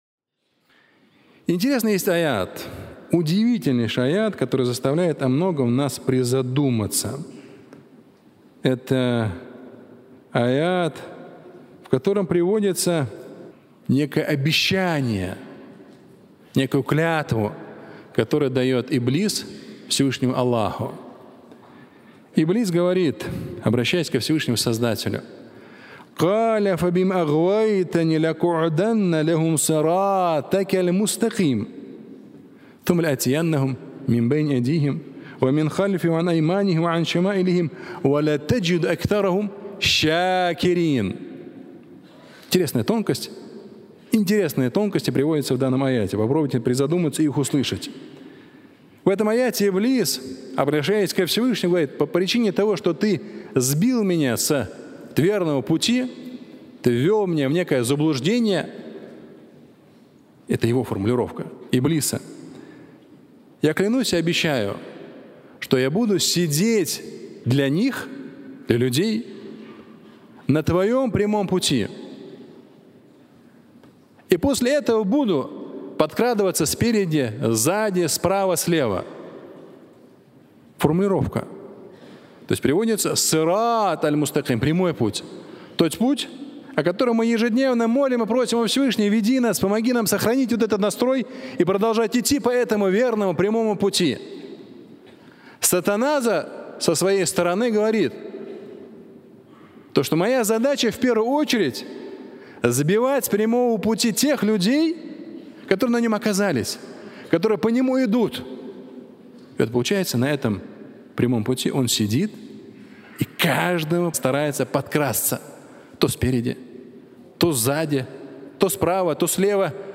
Иблис на прямом пути... (аудиолекция)